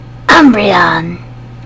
Audio / SE / Cries / UMBREON.mp3